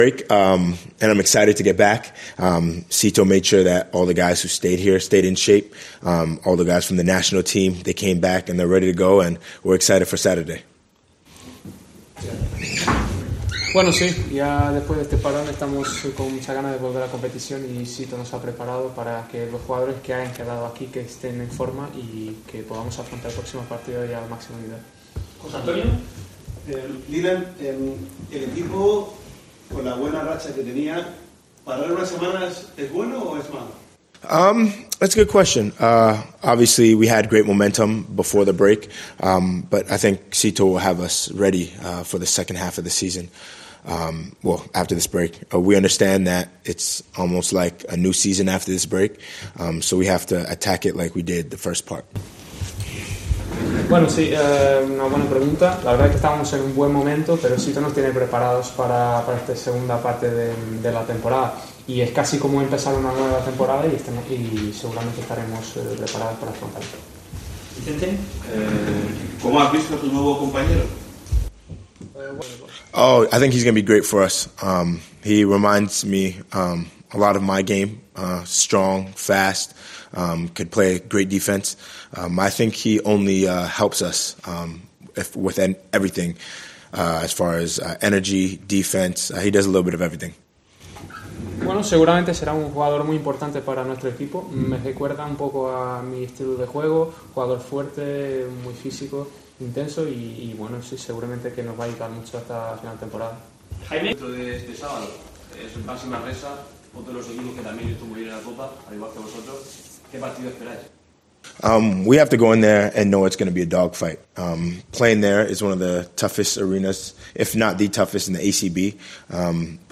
El canadiense Dylan Ennis, el hombre de la eterna sonrisa y uno de los líderes en el vestuario de UCAM Murcia CB, ha comparecido este jueves para analizar la situación del conjunto universitario en la previa para el regreso a la competición el próximo sábado frente a Baxi Manresa en el Nou Congost.